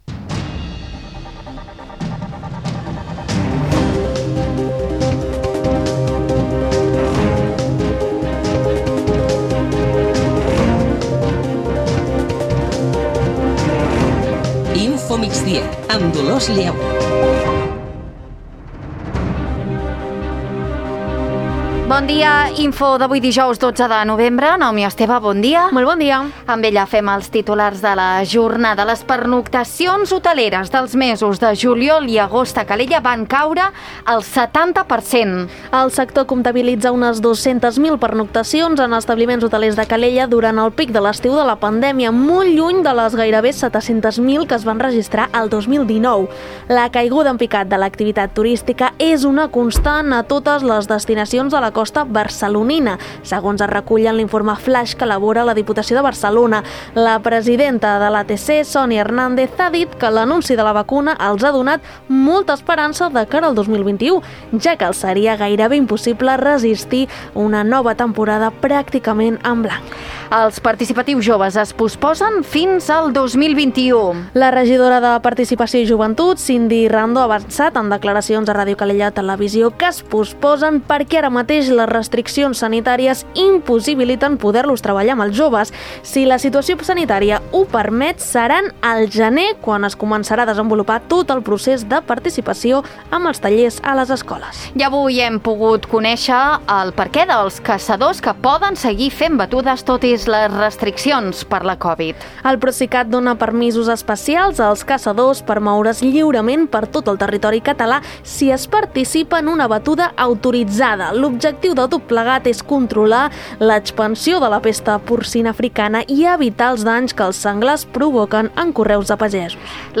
Notícies d'actualitat local i comarcal.